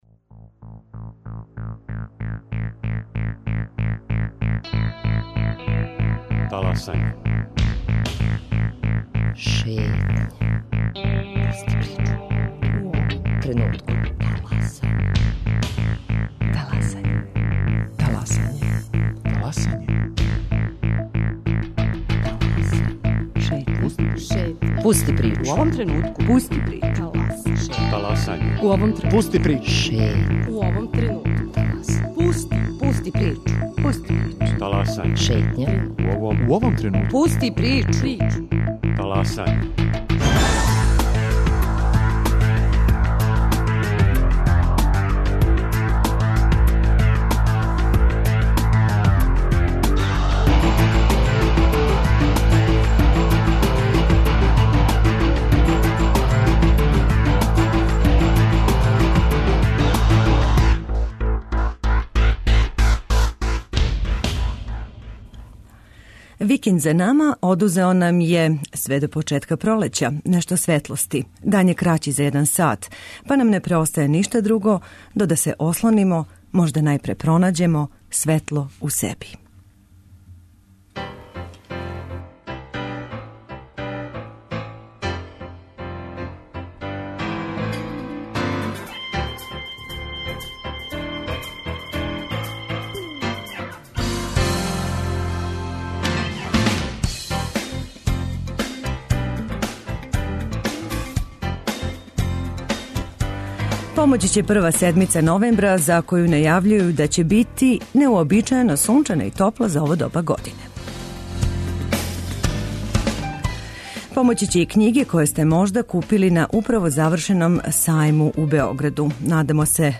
О тим темама разговарамо са гостом емисије